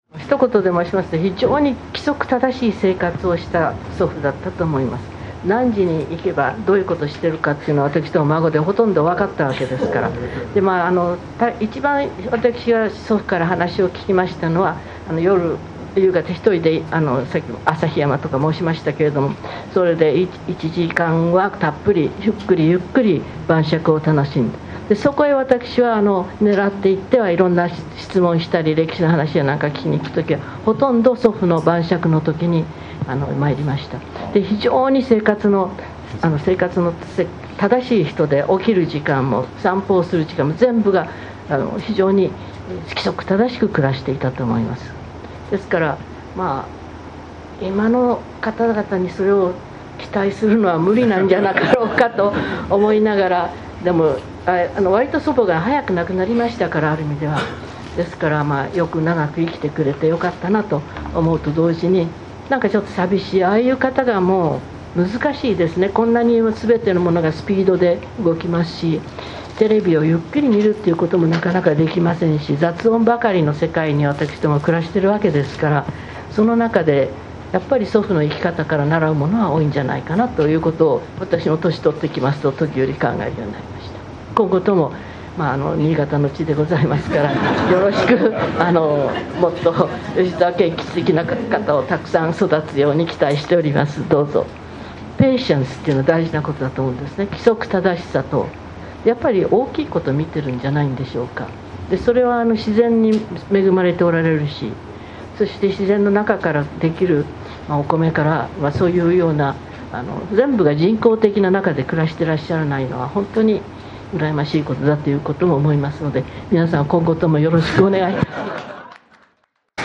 2015（平成27）年8月1日に行われた講話で、「謙吉の長女の長女です」と自己紹介されたことを思い出します。
「芳澤謙吉翁50回忌法要」で、謙吉翁が生まれ育った大字諏訪の生家跡にお立ちよりになられた時のことです。